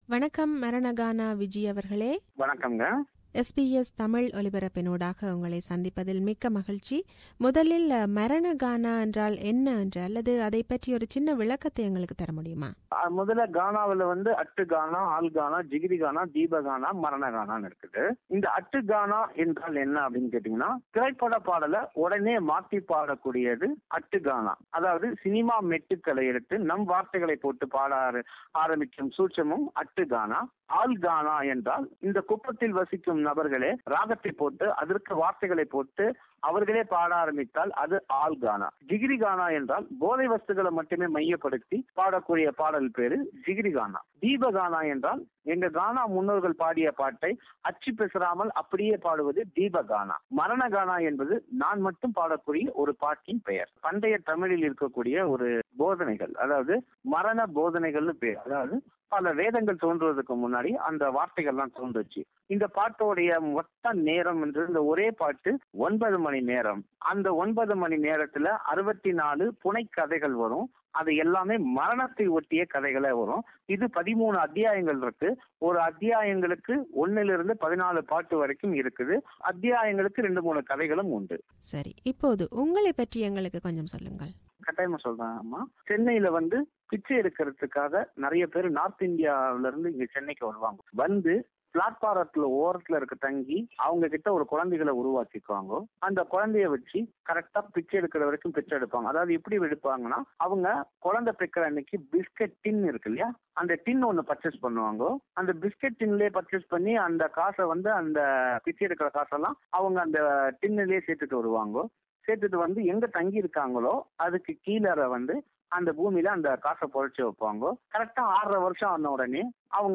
He opens up and shares his life story. This is the first part of the interview given to SBS Tamil.